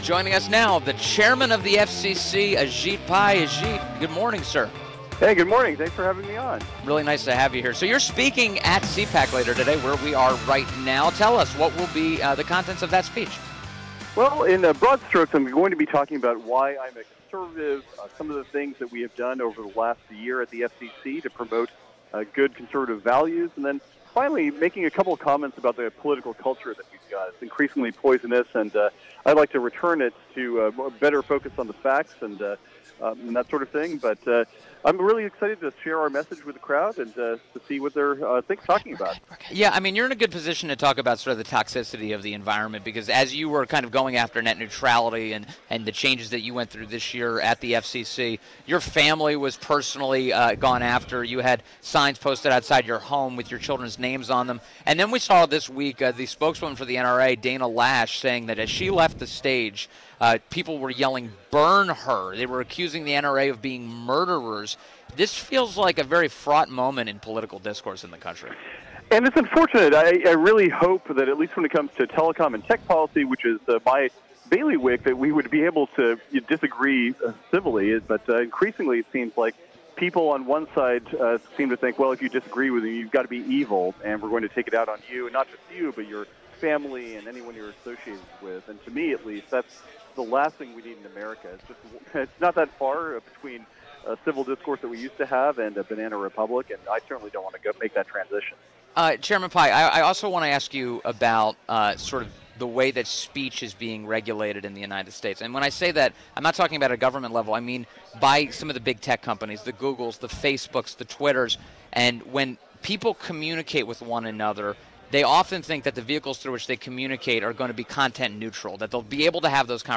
WMAL Interview - CPAC AJIT PAI - 02.23.18
INTERVIEW - AJIT PAI - Chairman of the Federal Communications Commission (FCC) – discussed FCC paving the way for innovation